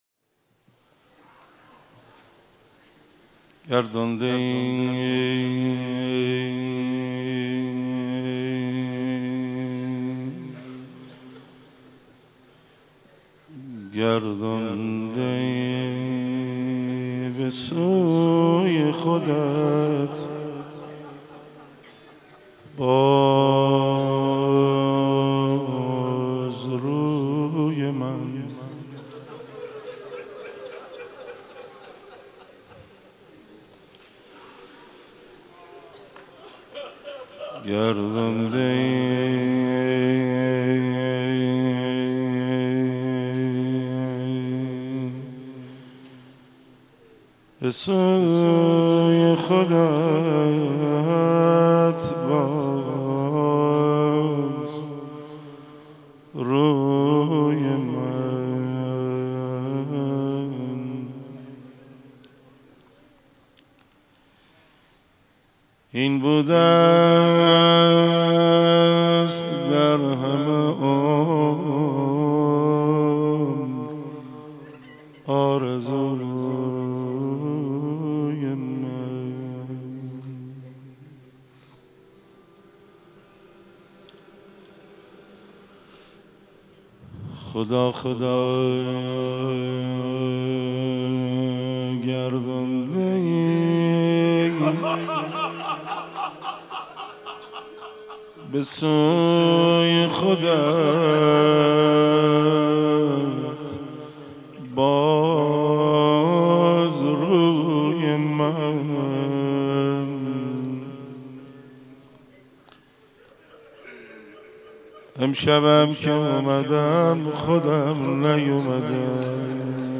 مناجات شب 19 رمضان.mp3